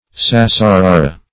Search Result for " sassarara" : The Collaborative International Dictionary of English v.0.48: Sassarara \Sas`sa*ra"ra\, n. [Perh. a corruption of certiorari, the name of a writ.]